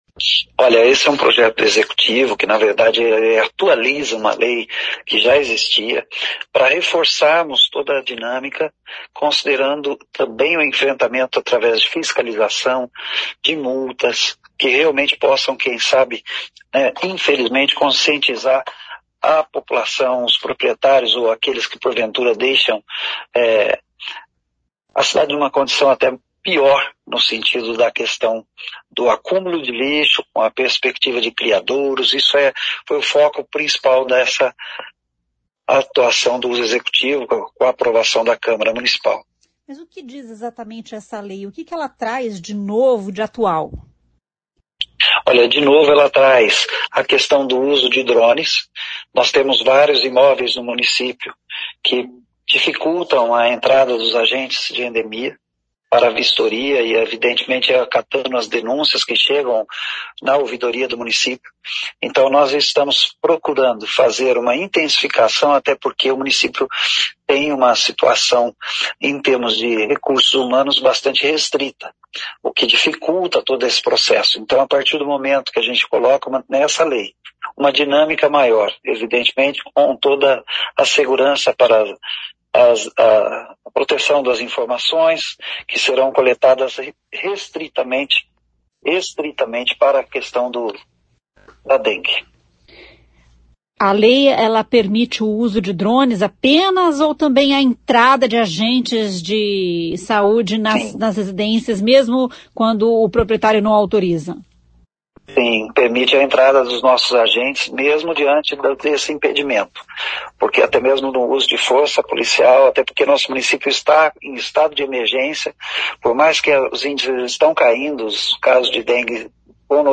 O prefeito de Mandaguaçu, Beto Dentista, explica que a iniciativa é do Executivo.